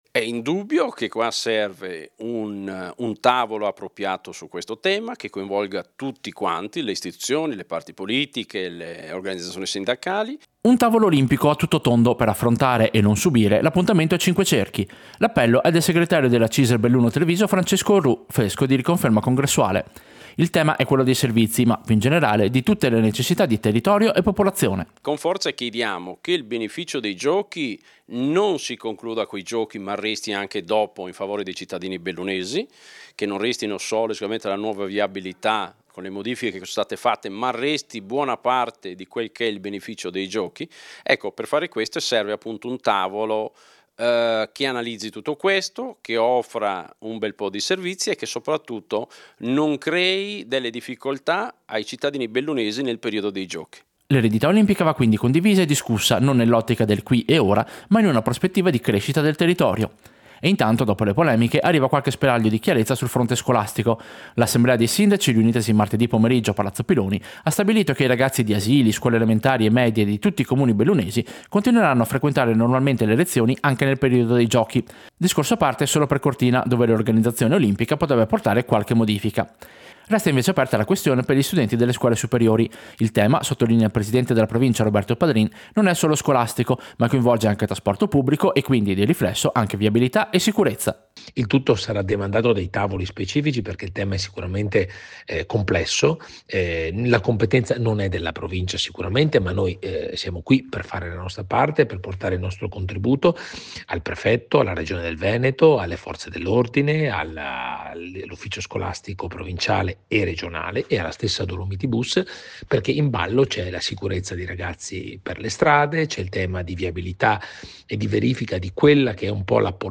Servizio-Cisl-tavolo-Olimpiadi.mp3